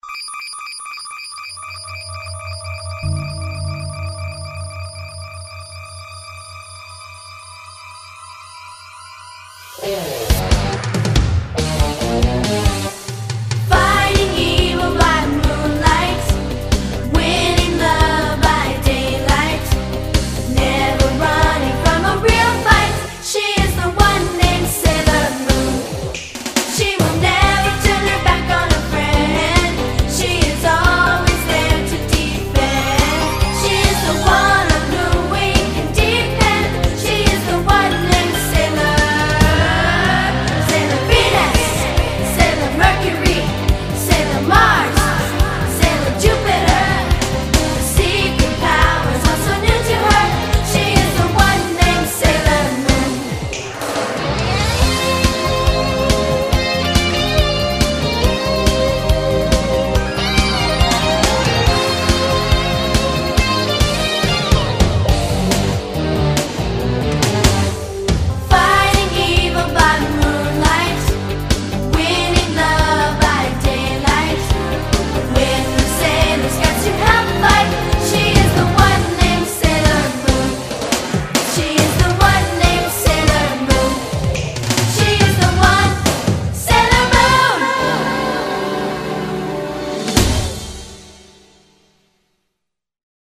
BPM140-140
Audio QualityCut From Video